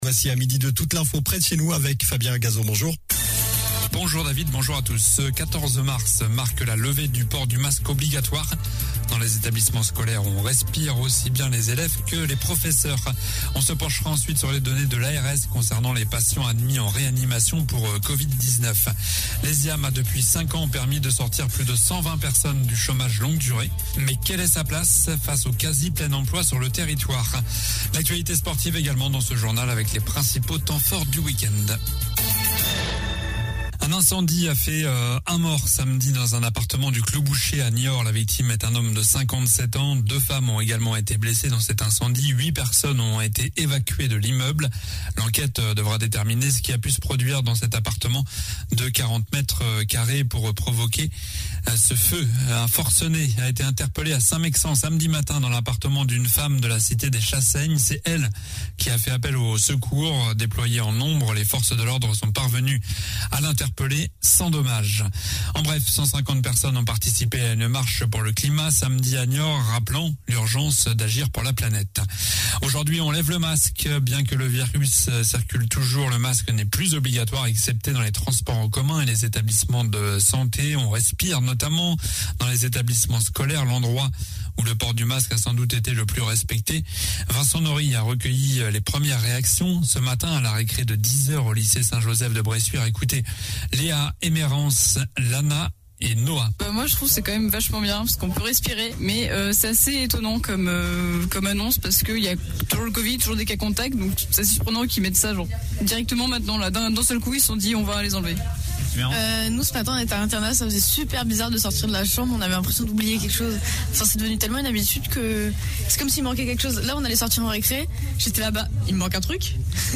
Journal du lundi 14 mars